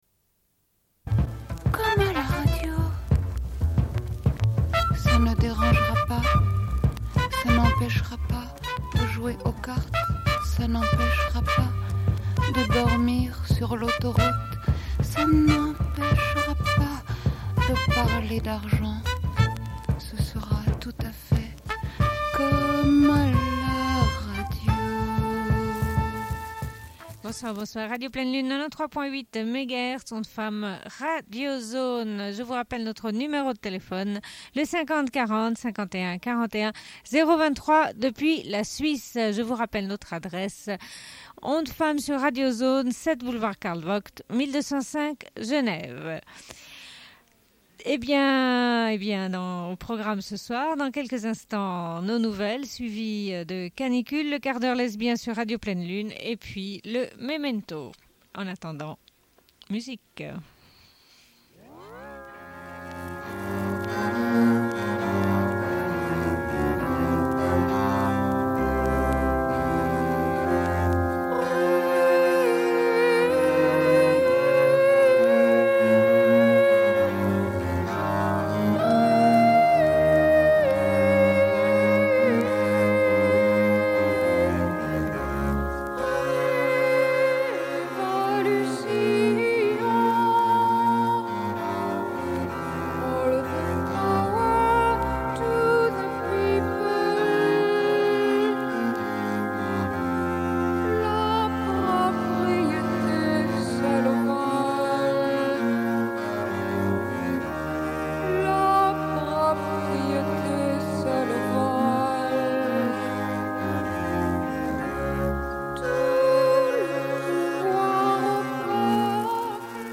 Bulletin d'information de Radio Pleine Lune du 06.05.1992 - Archives contestataires
Une cassette audio, face B